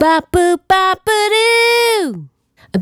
Bop Boo Wah 085-D#.wav